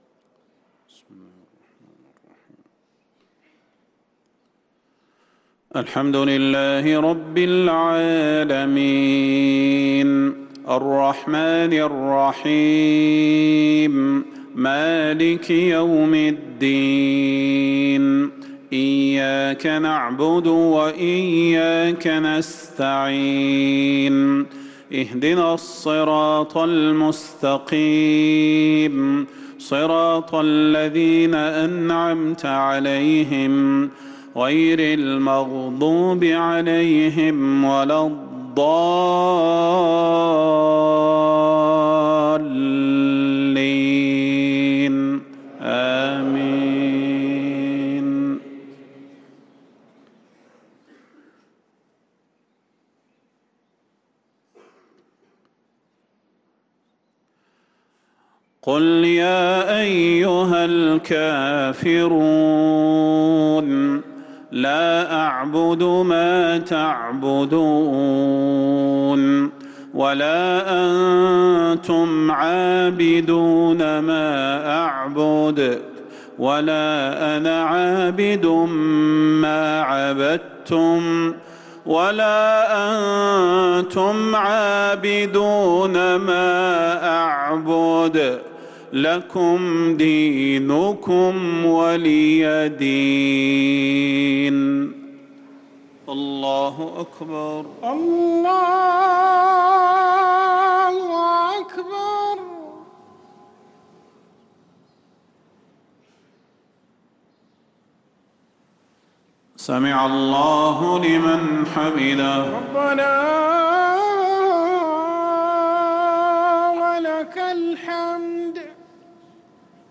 صلاة المغرب للقارئ صلاح البدير 10 رجب 1445 هـ
تِلَاوَات الْحَرَمَيْن .